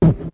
Beatbox 1.wav